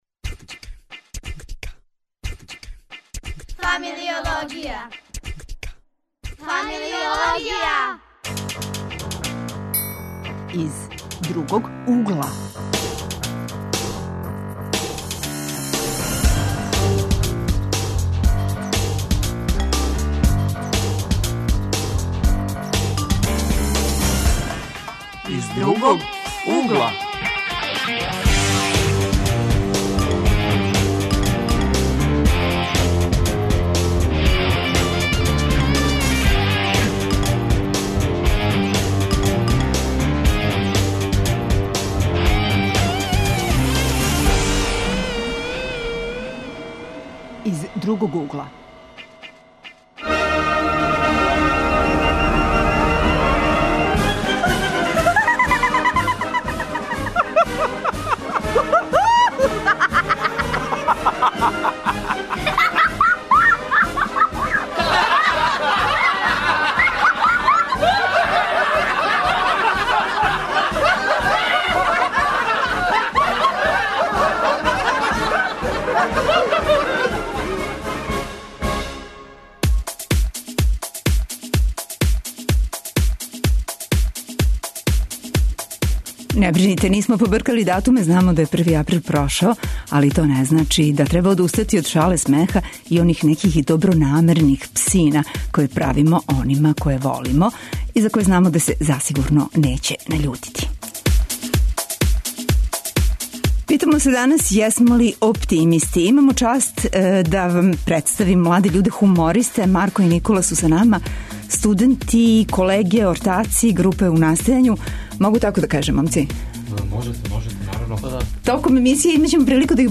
Данас смо у знаку оптимизма, смеха, шале... Гости су нам млади хумористи, студенти, који први пут данас приказују свој радио скеч.